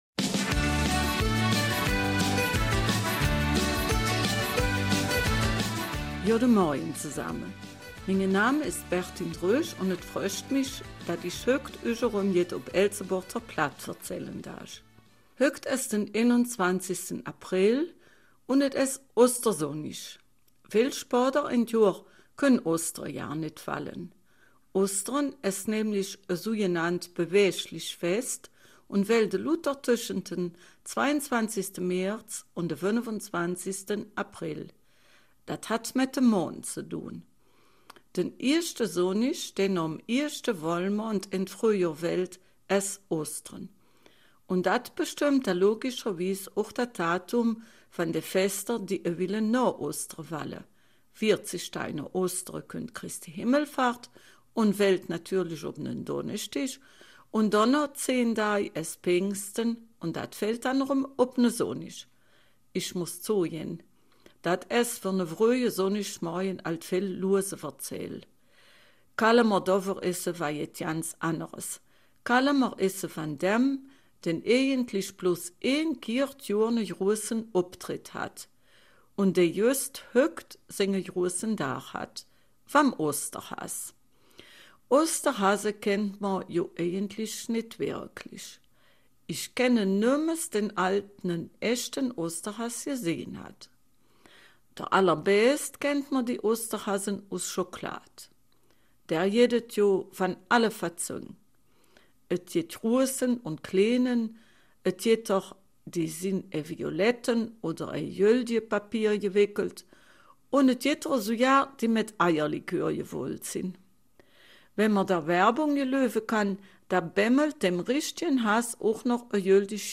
Eifeler Mundart: Der Osterhase